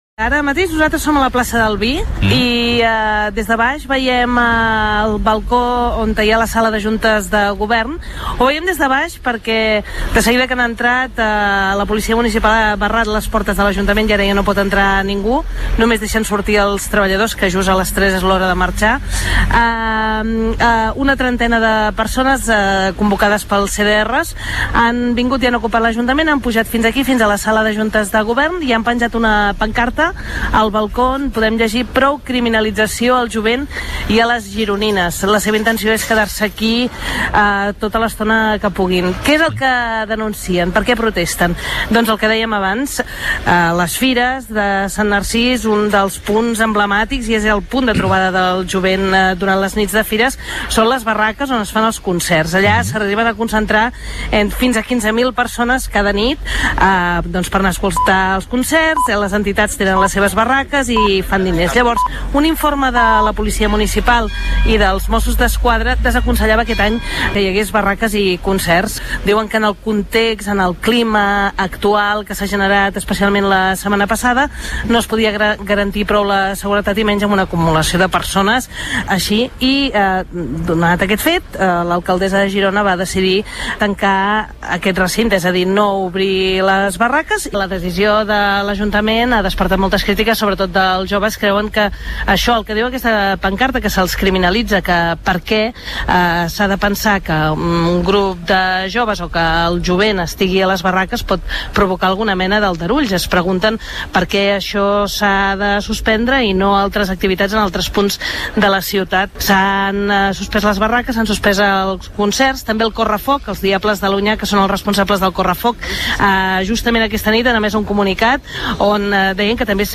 Catalunya migdia - crònica de Girona - Catalunya Ràdio - 2019